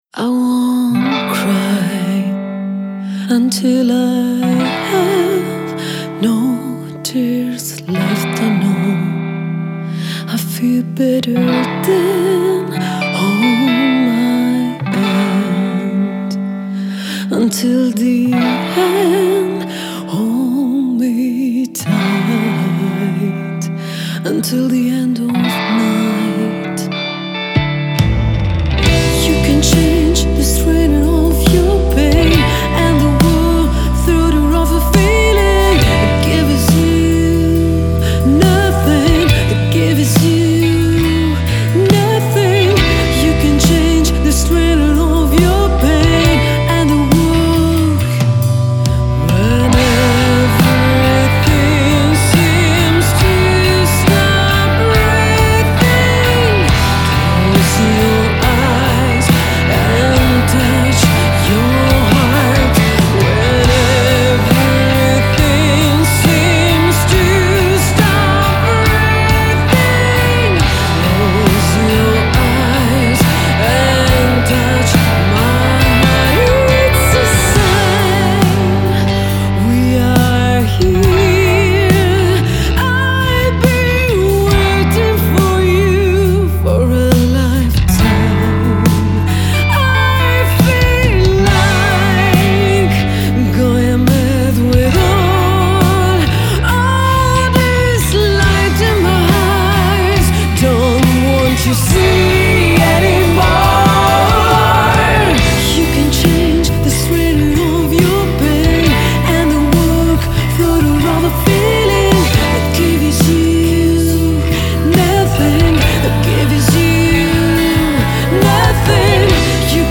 Genere: Alt. Rock.